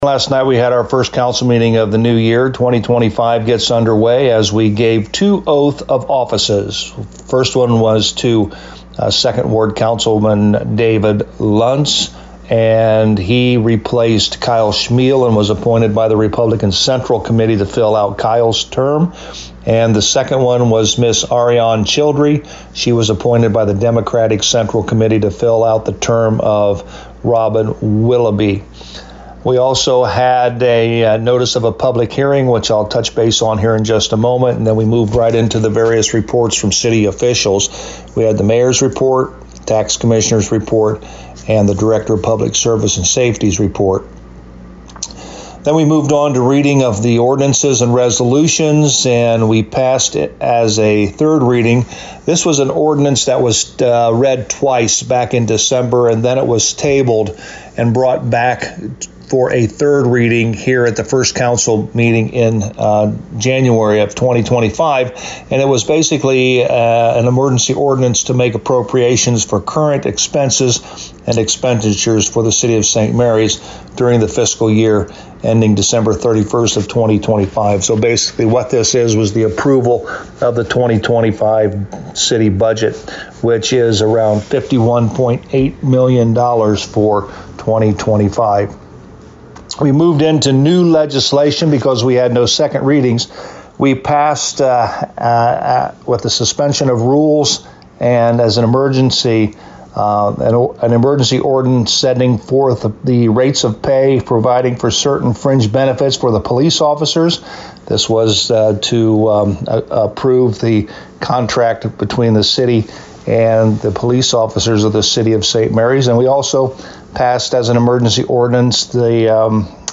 To hear the summary with Mayor Joe Hurlburt: